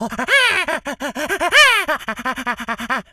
monkey_chatter_angry_06.wav